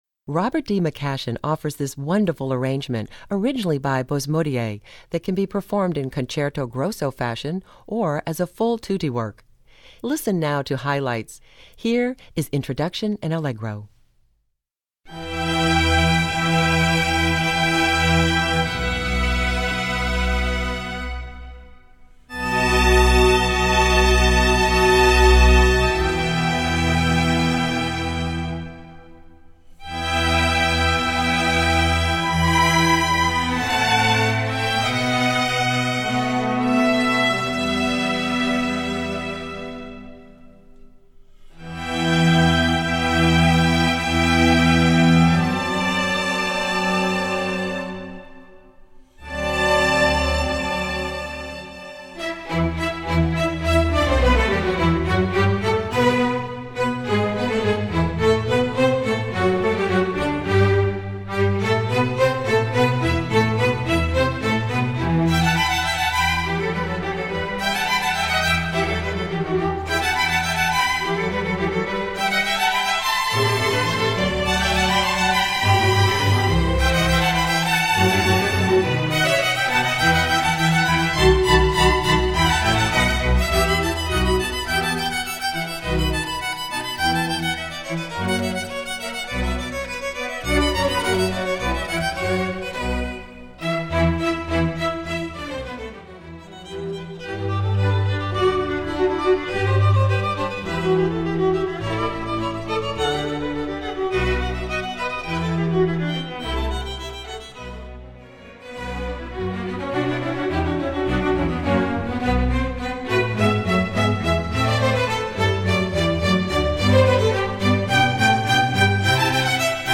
Composer: Spiritual
Voicing: String Orchestra